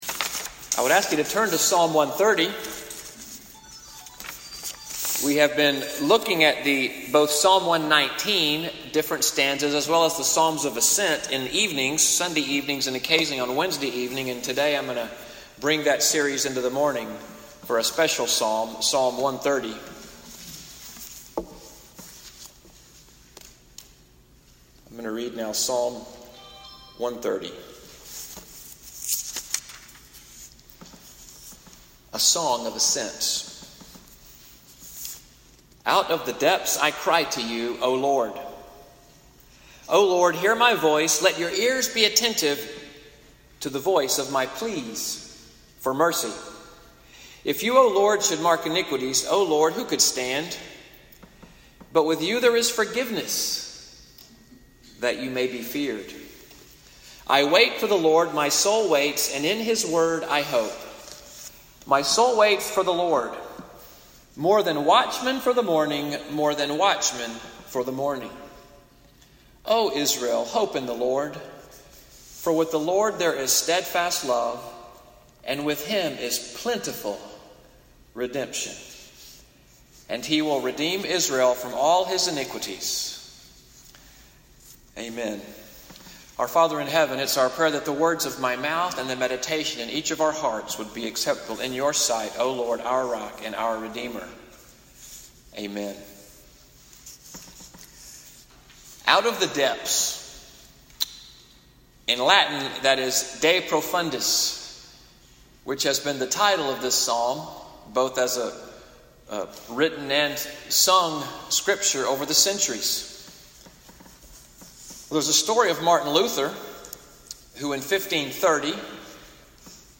SUNDAY MORNING WORSHIP NCPC-Selma, 11am, audio from the sermon, “The Sinner & the Savior,” (24:30), July 15, 2018.